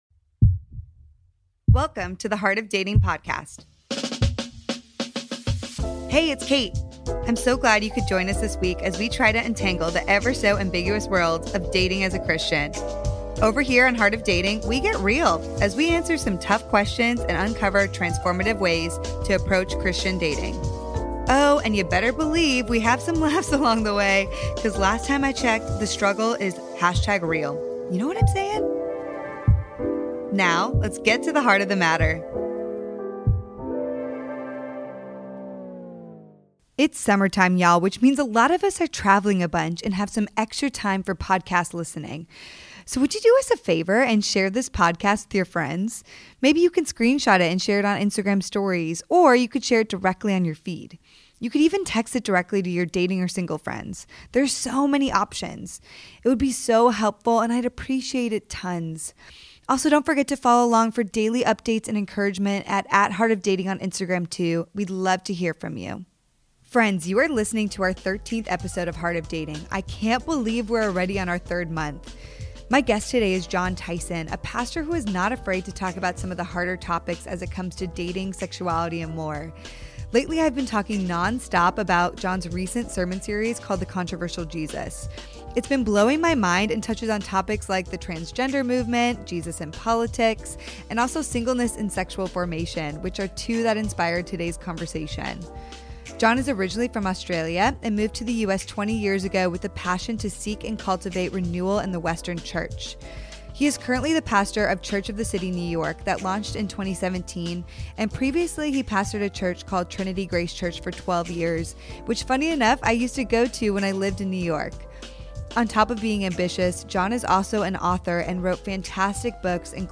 He is originally from Australia, has authored many books, has an incredible accent, and has pastored a couple of prominent big churches in New York.